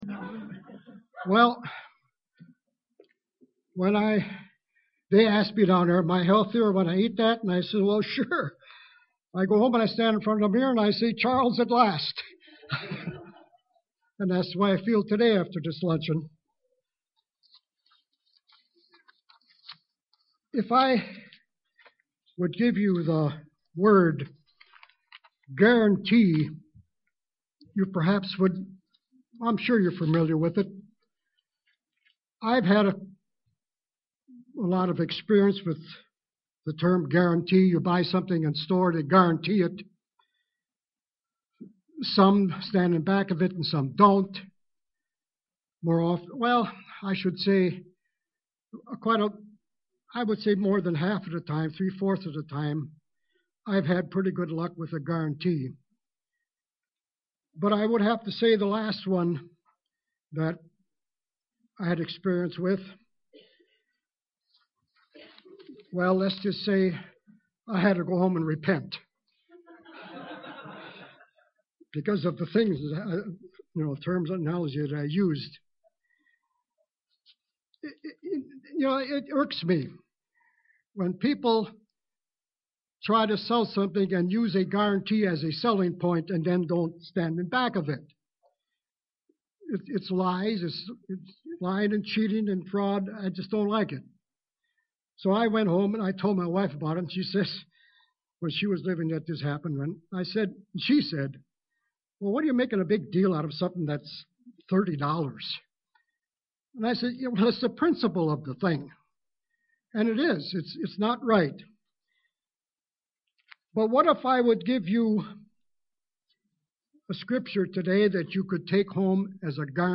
How to guarantee keeping your name in the book of life. This message was given on the Last Day of Unleavened Bread.
UCG Sermon Studying the bible?